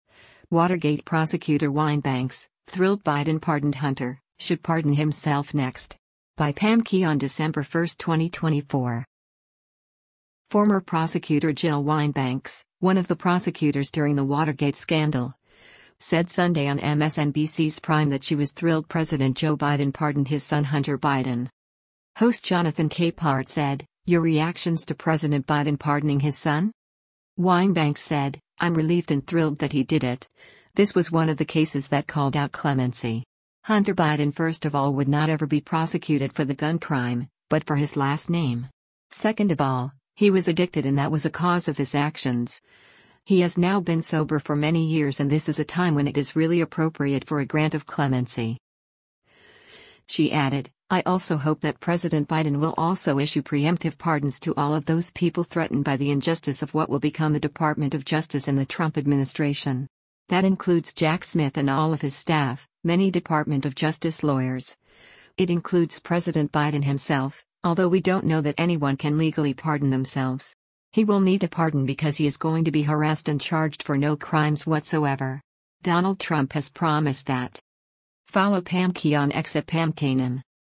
Former prosecutor Jill Wine-Banks, one of the prosecutors during the Watergate scandal, said Sunday on MSNBC’s “Prime” that she was “thrilled” President Joe Biden pardoned his son Hunter Biden.